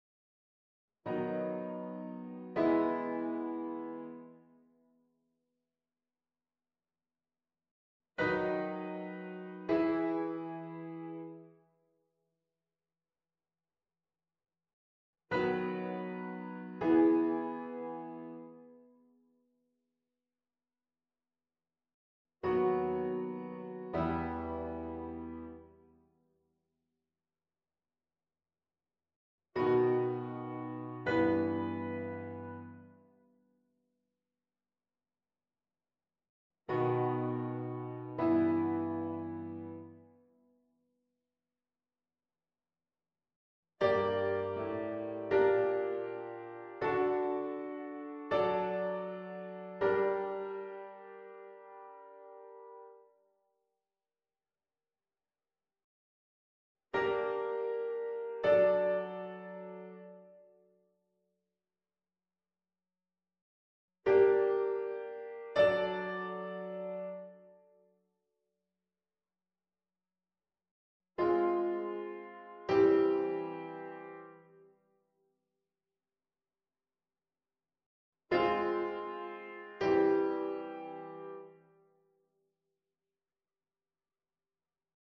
verdubbeling in I6 en V6